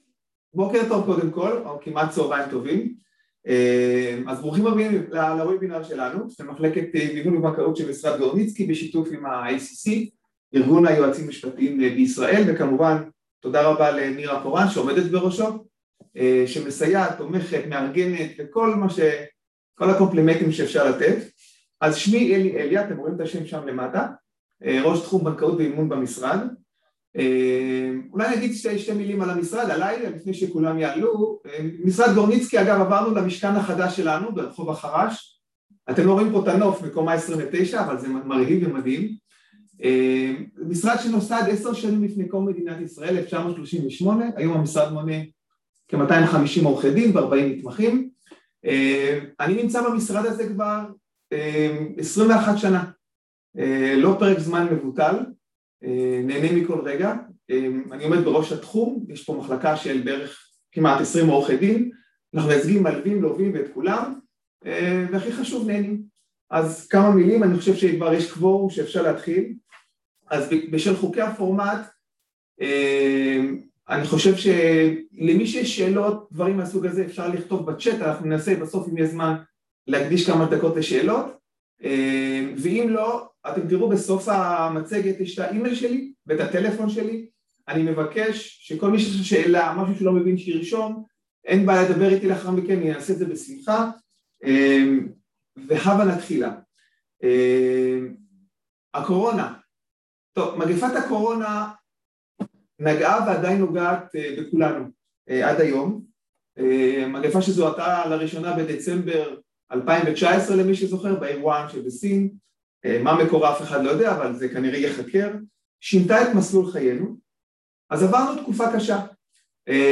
וובינר אפקט הקורונה או חזל״ש?
וובינר-בנקאות.m4a